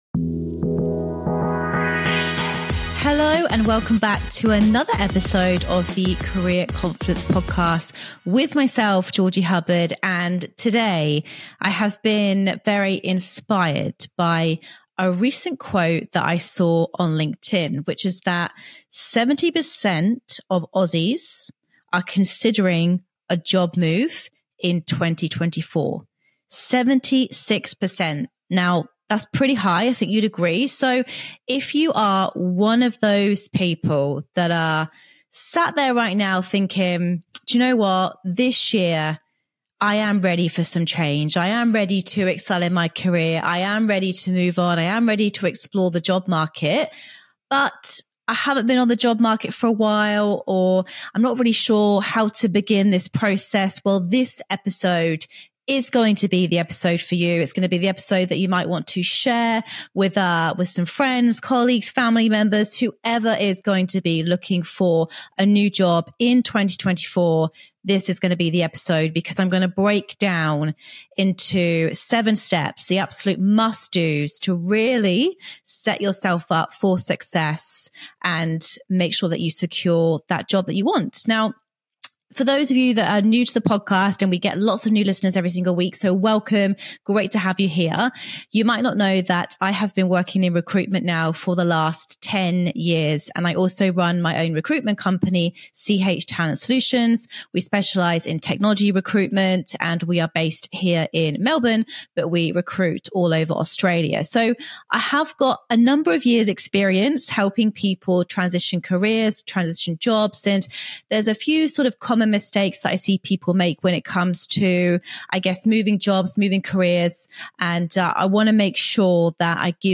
In today's solo episode